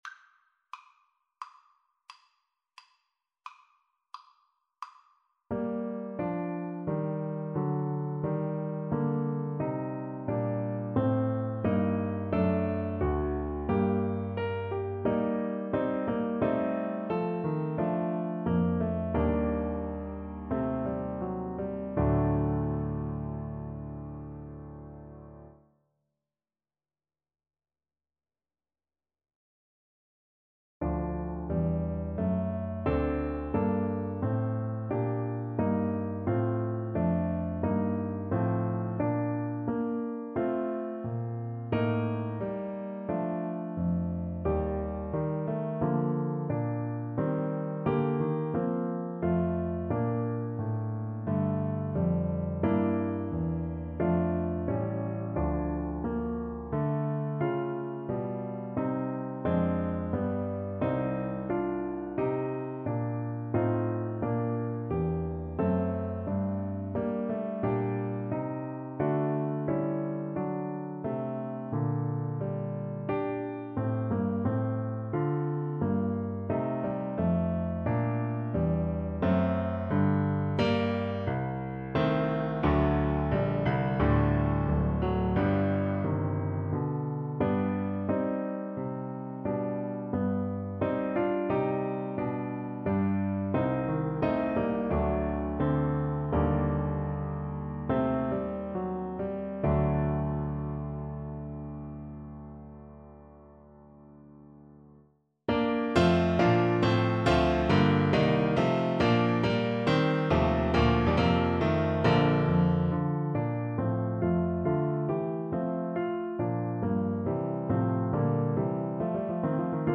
• Unlimited playalong tracks
Adagio =88
4/4 (View more 4/4 Music)
Classical (View more Classical Cello Music)